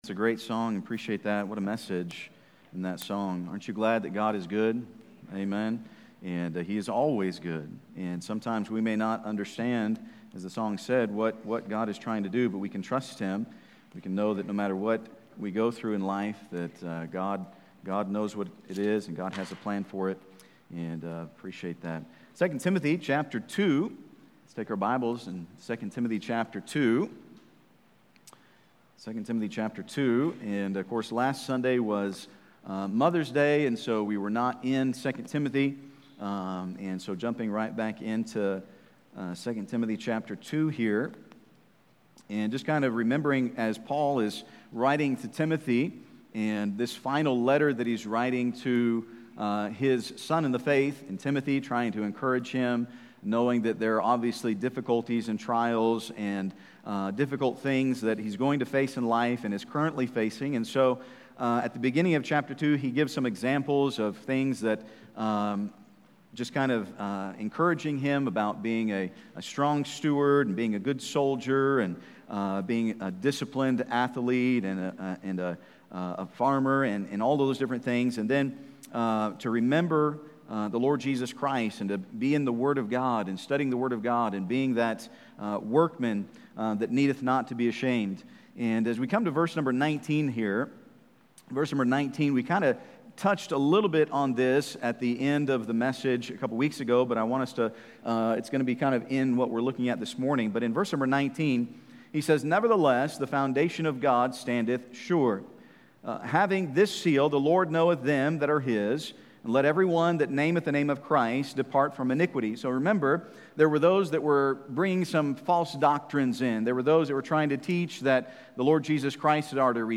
Sermons | First Baptist Church